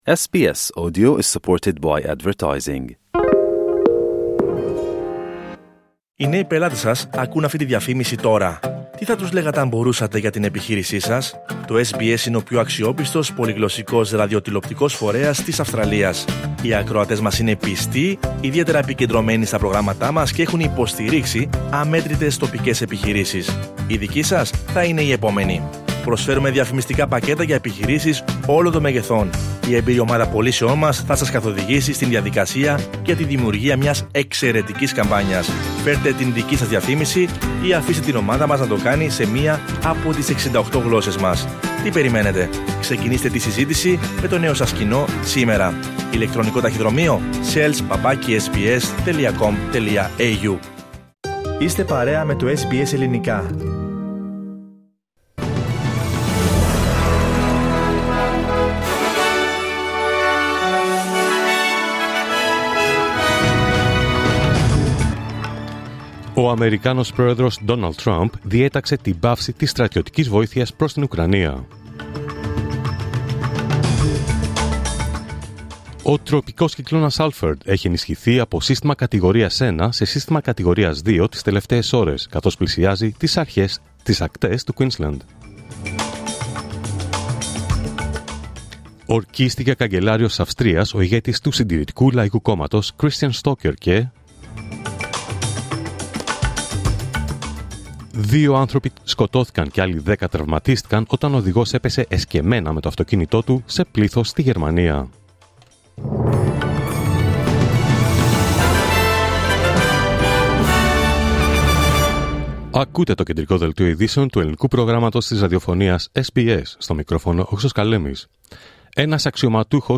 Δελτίο Ειδήσεων Τρίτη 4 Μαρτίου 2025